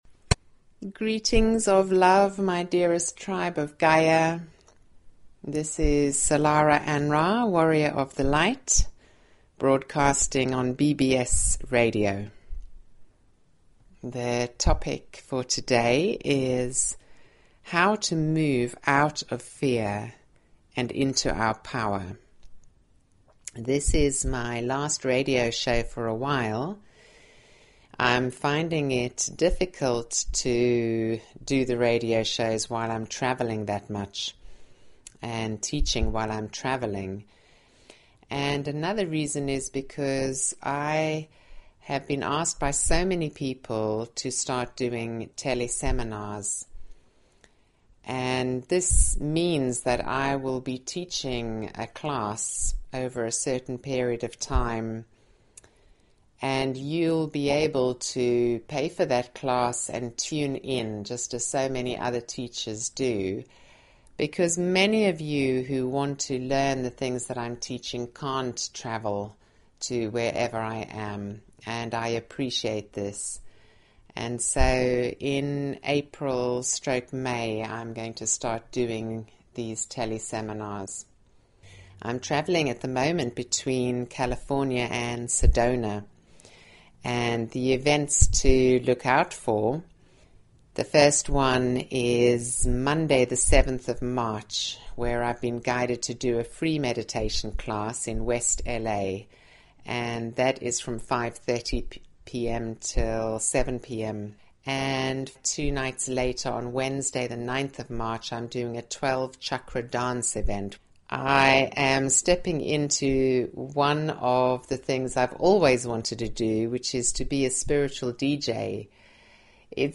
Talk Show Episode, Audio Podcast, Illumination_from_the_Councils_of_Light and Courtesy of BBS Radio on , show guests , about , categorized as